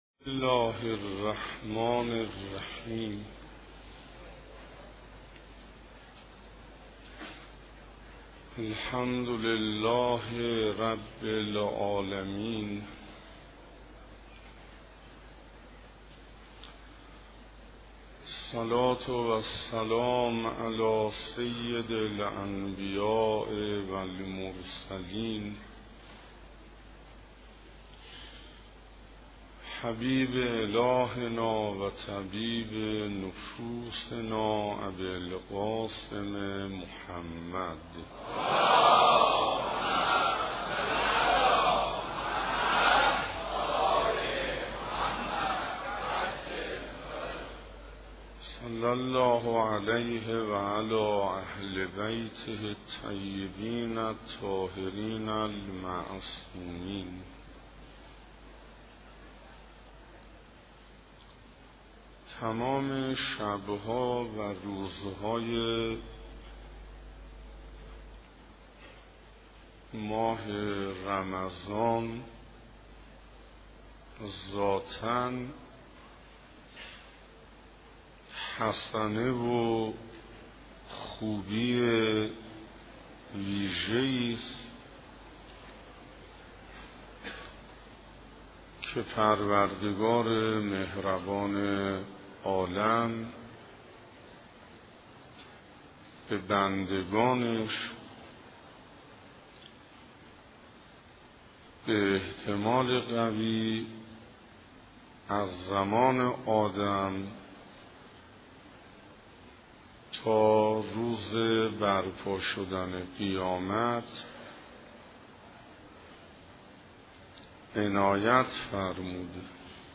سخنرانی حجت الاسلام حسین انصاریان